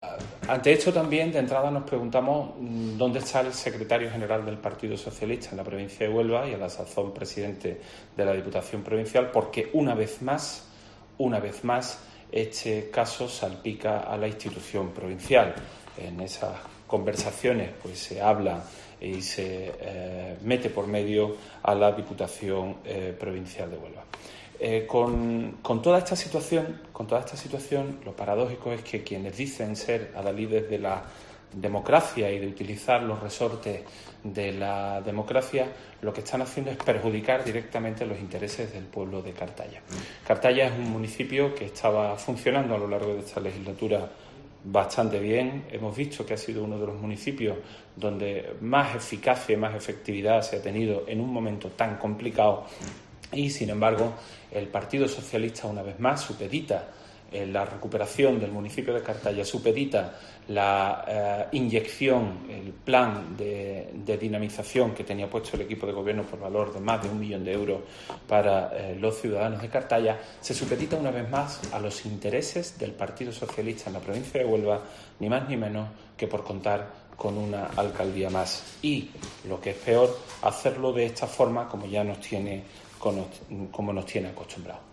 Juan Carlos Duarte, portavoz del PP en la Diputación Provincial